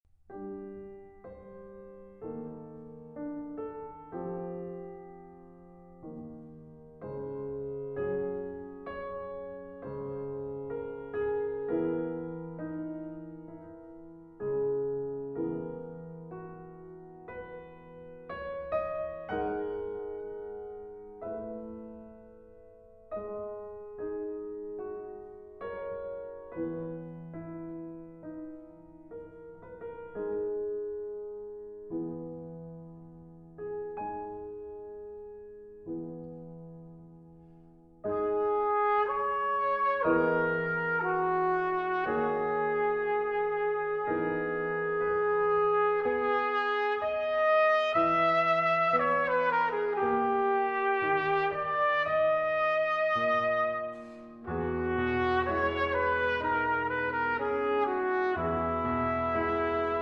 Trumpet
Piano